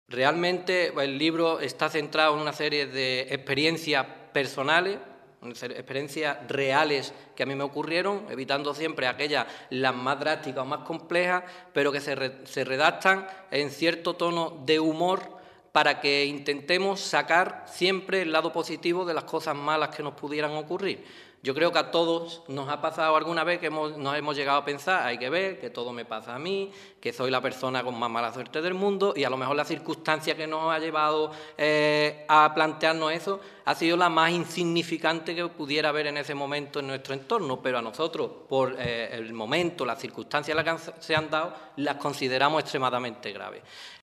Miguel Ángel Carrero Nieto ha presentado en el Palacio Provincial su tercer libro. Se trata de un conjunto de relatos breves que llevan por título ‘¡Ay, Omá!’.